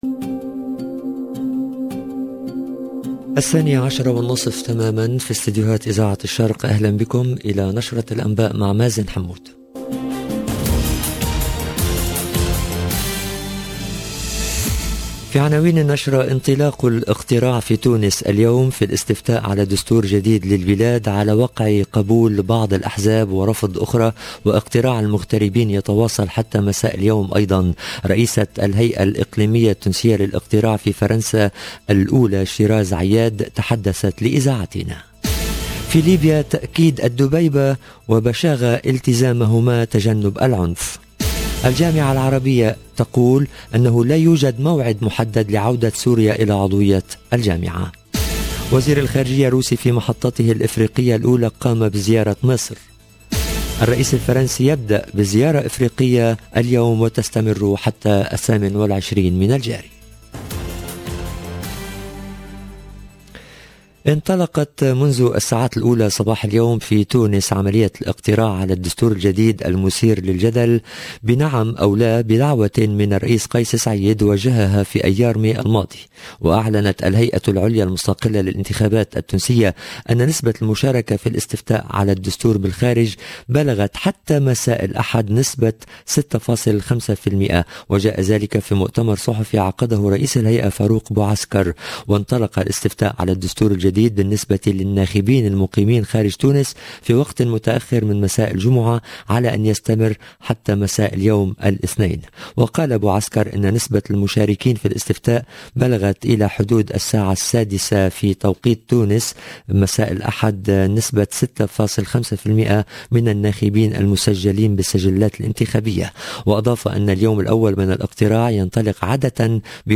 LE JOURNAL DE MIDI 30 EN LANGUE ARABE DU 25/07/22